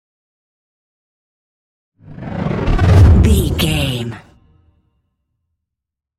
Whoosh deep
Sound Effects
dark
tension
whoosh